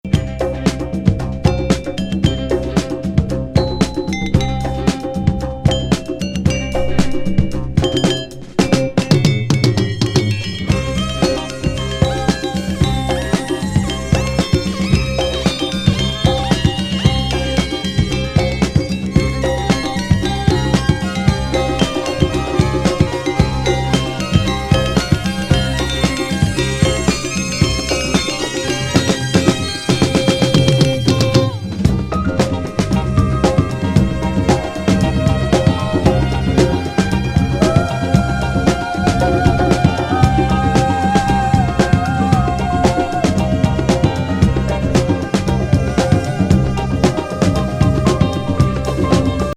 独キーボード奏者のエレクトロニクス・ジャズ80年作!女性VOCAL入アヴァン・